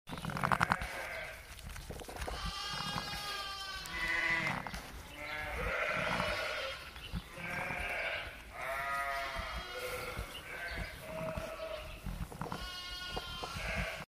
Best sound! New lamb straight out of the oven. Tonnes of new lambs on the farm.